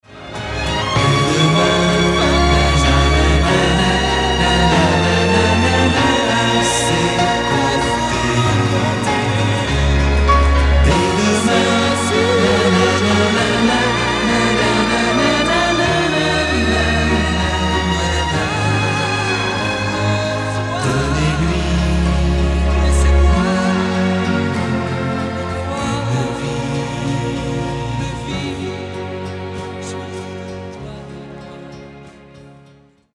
Mirari ² - Fichier "choeurs.mp3"